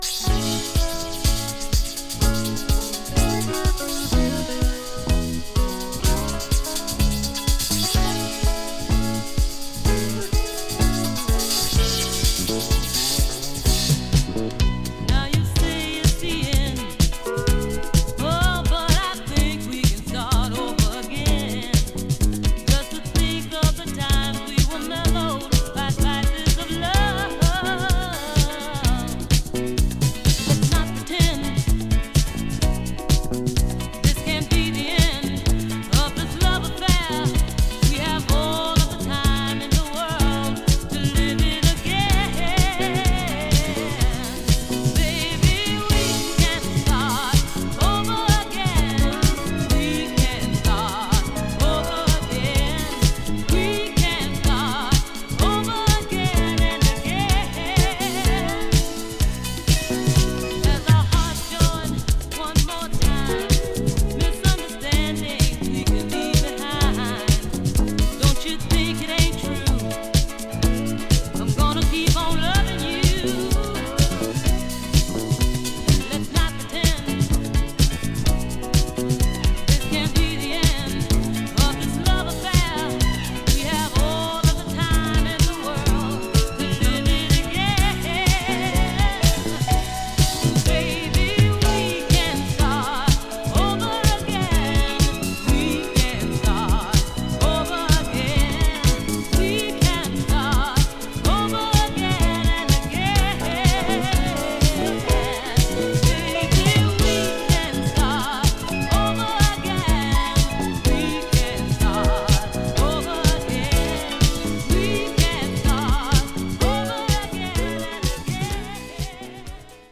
【DISCO】 【SOUL】